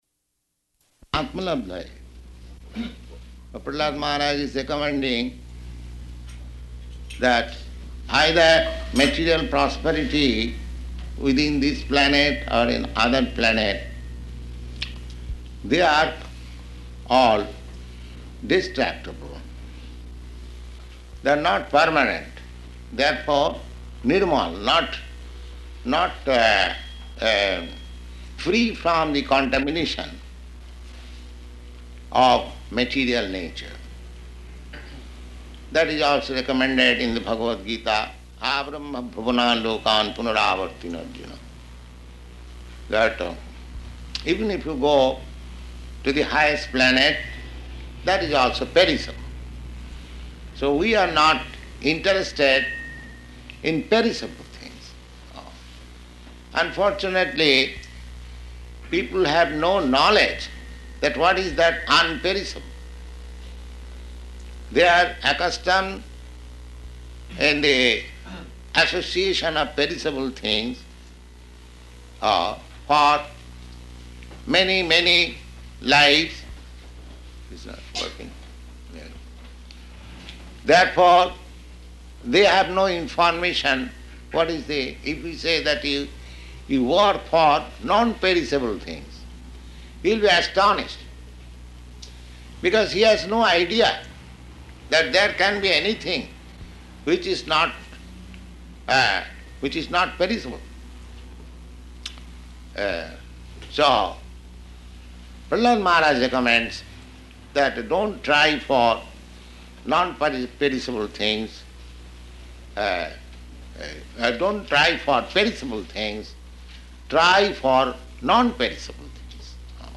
Śrīmad-Bhāgavatam 7.7.40-44 [partially recorded]
Śrīmad-Bhāgavatam 7.7.40-44 [partially recorded] --:-- --:-- Type: Srimad-Bhagavatam Dated: March 20th 1967 Location: San Francisco Audio file: 670320SB-SAN_FRANCISCO.mp3 Prabhupāda: ...[indistinct] of life.